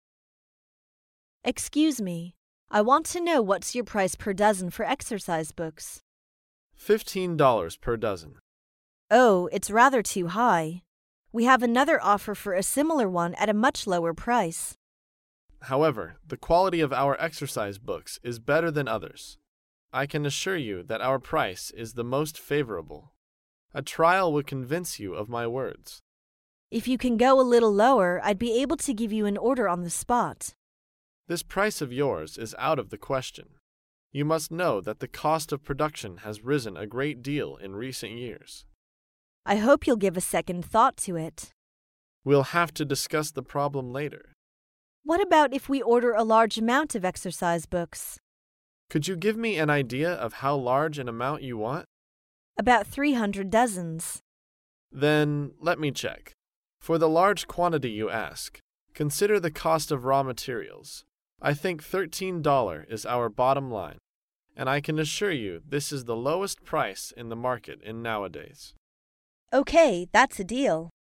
在线英语听力室高频英语口语对话 第312期:批货优惠(2)的听力文件下载,《高频英语口语对话》栏目包含了日常生活中经常使用的英语情景对话，是学习英语口语，能够帮助英语爱好者在听英语对话的过程中，积累英语口语习语知识，提高英语听说水平，并通过栏目中的中英文字幕和音频MP3文件，提高英语语感。